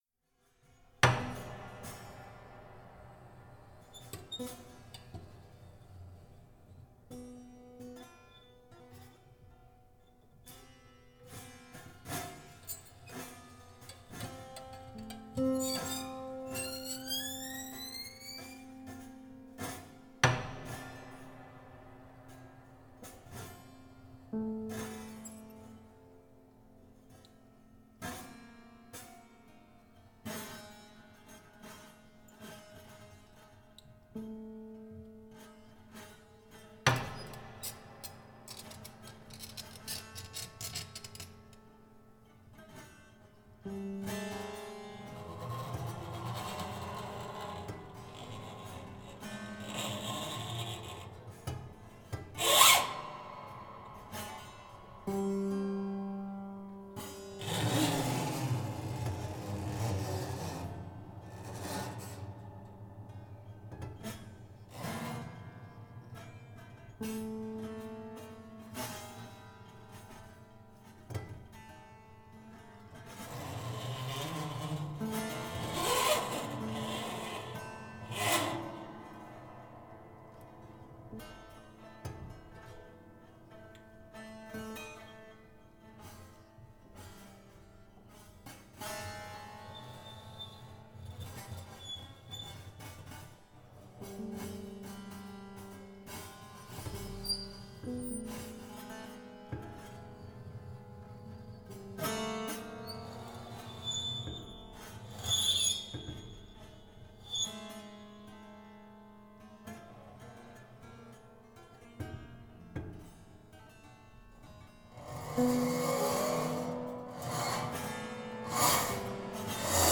PIANO SESSION
Piano session.mp3